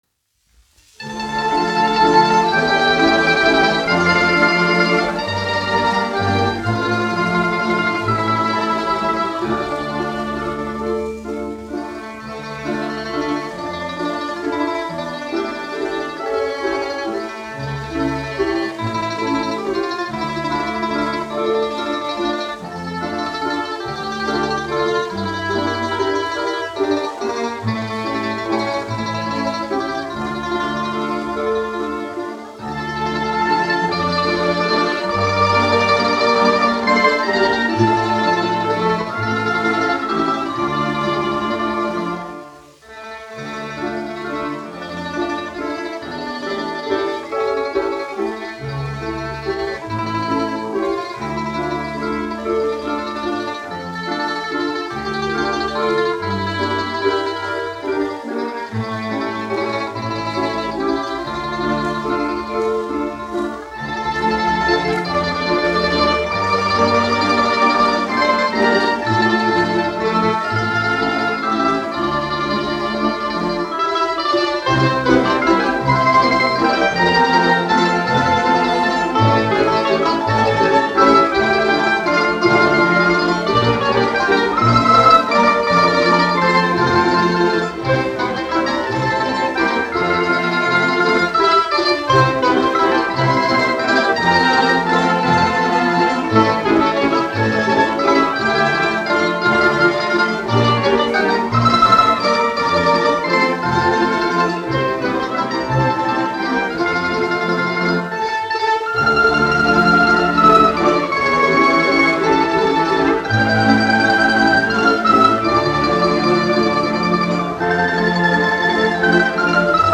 1 skpl. : analogs, 78 apgr/min, mono ; 25 cm
Balalaiku orķestra mūzika
Skaņuplate